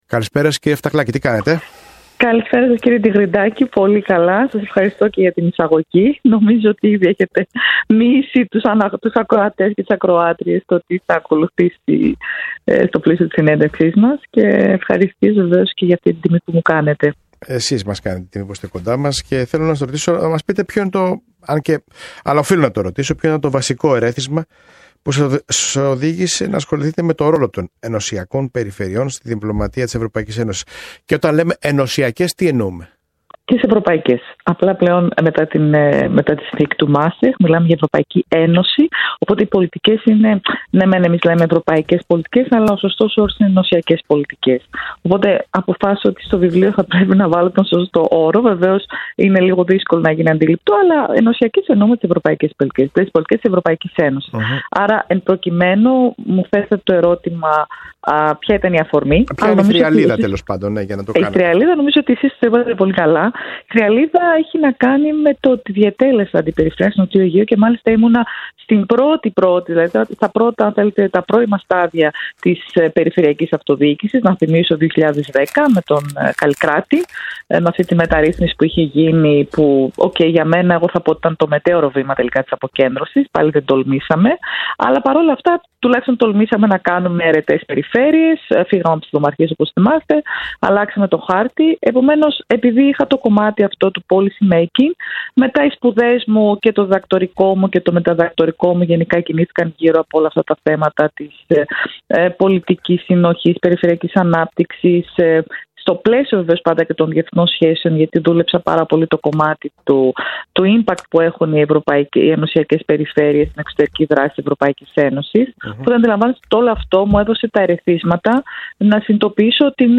Στη συνέντευξη αναφέρθηκε στο τι την ενέπνευσε να ασχοληθεί με το συγκεκριμένο πεδίο, στις προκλήσεις που αντιμετωπίζουν οι ενωσιακές περιφέρειες, καθώς και στις καλές πρακτικές που παρουσιάζει μέσα από το βιβλίο της.